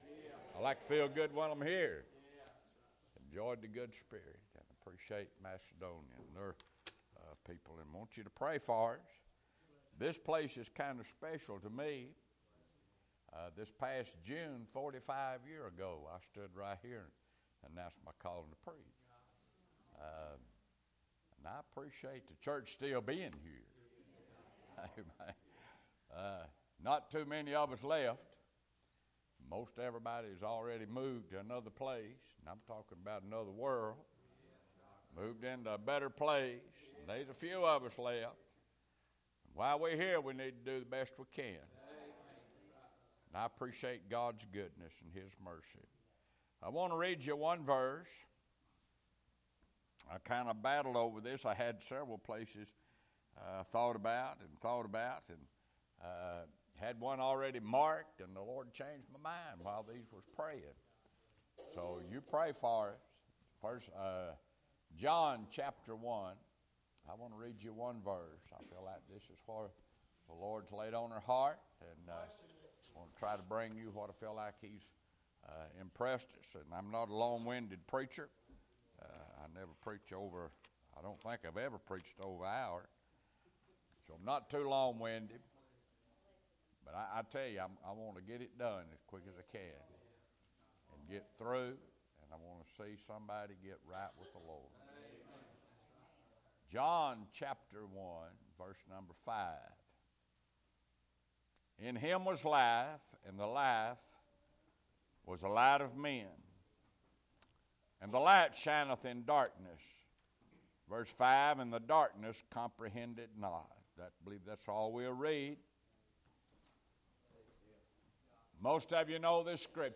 Sermon media